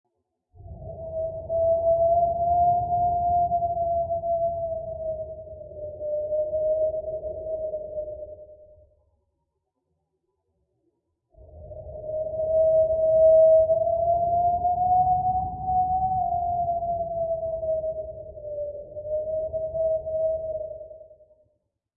Download Spooky sound effect for free.